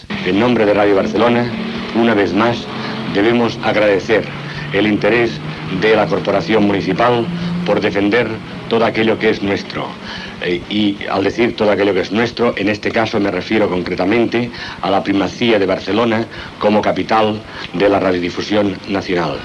Paraules
a l'acte de lliurament de la medalla d'or de la Diputació de Barcelona, amb motiu dels 40 anys de l'emissora.